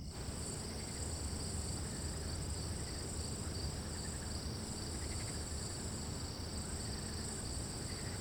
室外院子2.wav